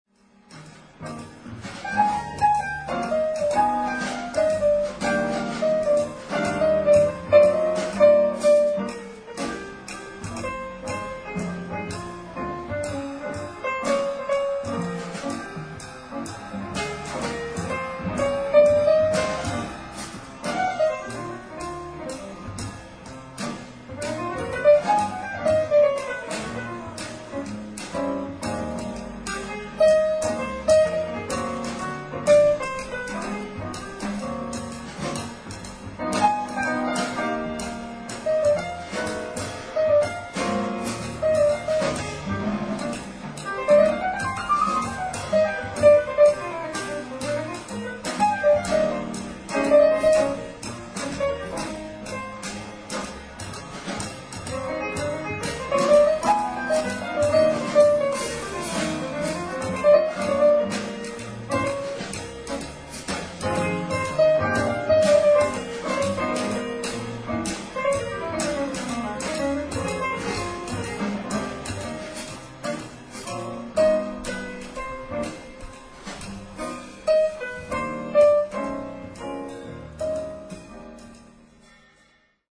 ライブ・アット・ペララーダ城音楽祭、ペララーダ、スペイン 07/20/2003
※試聴用に実際より音質を落としています。